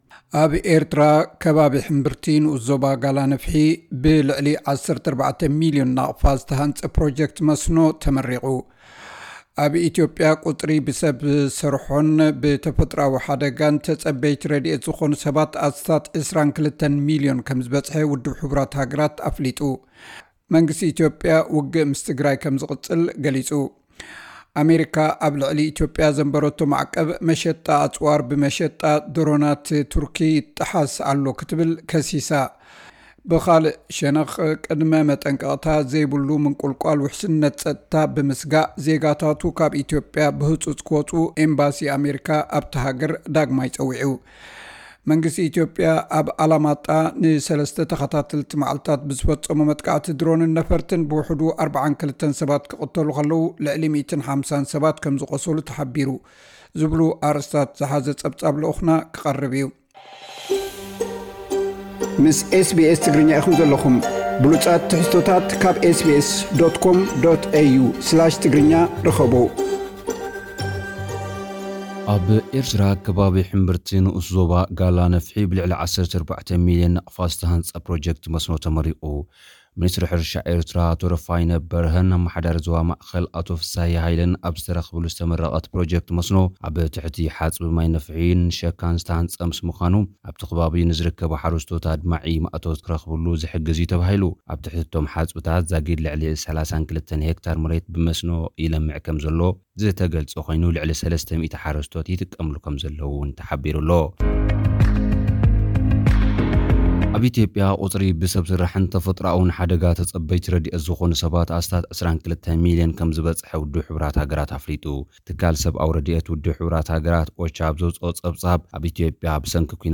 ዝብሉ ኣርእስታት ዝሓዘ ጸብጻብ ልኡኽና ቀሪቡ ኣሎ።